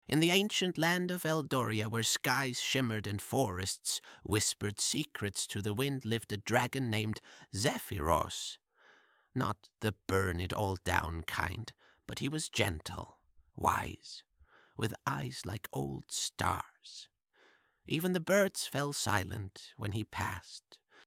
multilingual text-to-speech
The most expressive Text to Speech model
"voice": "Grimblewood",